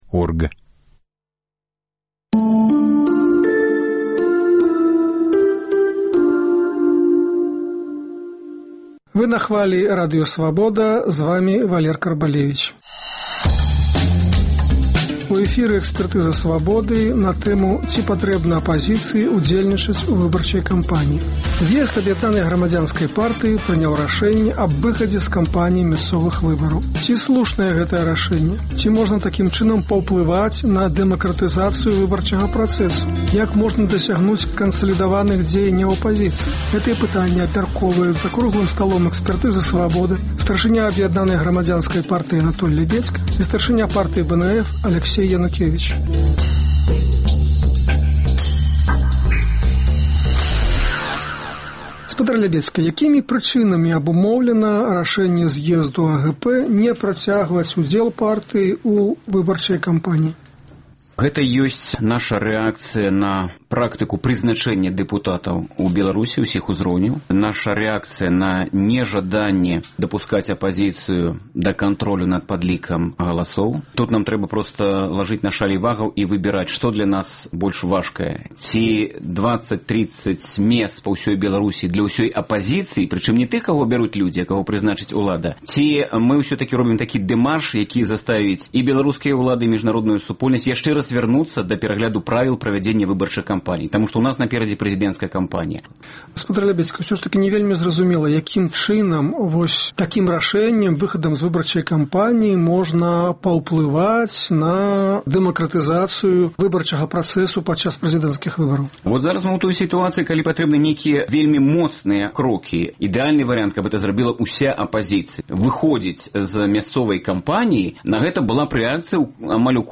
Як можна дасягнуць кансалідаваных дзеяньняў апазыцыі? Гэтыя пытаньні абмяркоўваюць за круглым сталом "Экспэртызы "Свабоды” старшыня Аб’яднанай грамадзянскай партыі Анатоль Лябедзька і старшыня Партыі БНФ Аляксей Янукевіч.